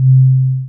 First, listen to each of these frequencies in isolation (click the links below to hear each frequency - these tones were generated with computer software):
130.81 Hz